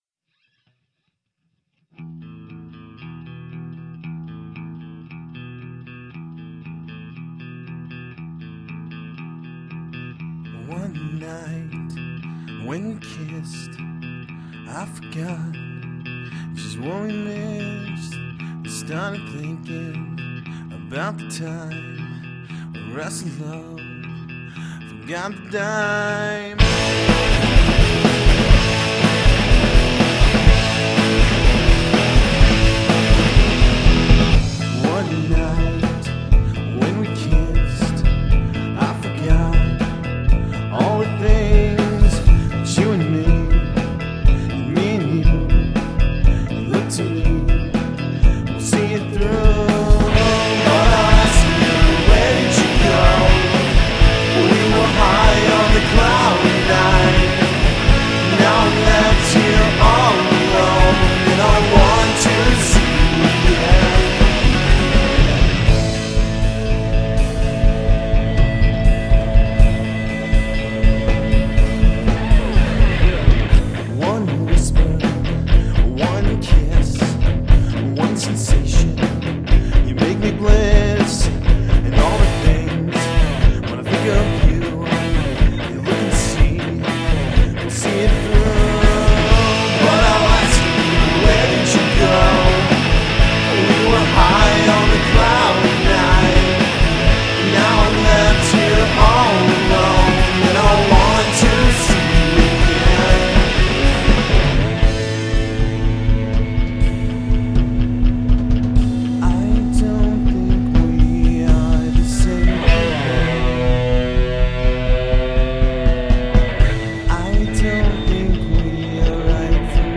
drums
melodic, vocal-harmony laden indie rock
bass guitar